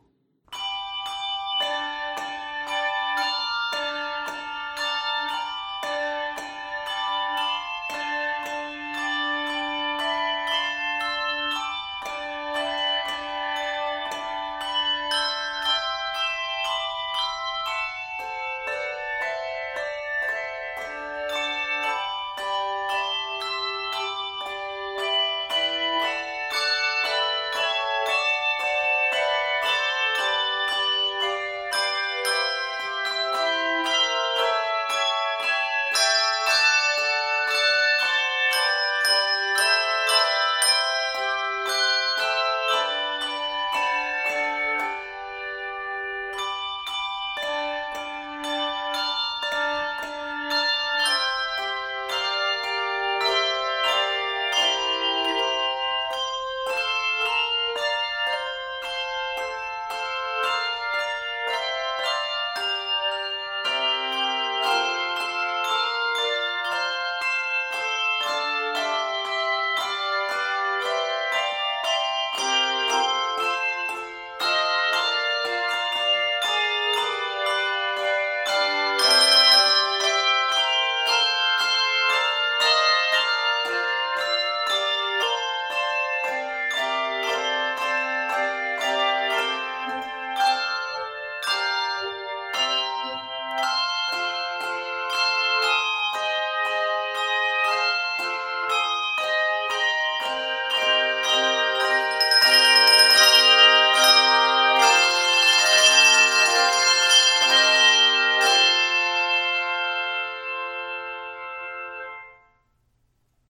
Bold, exciting, and approachable
Octaves: 2-3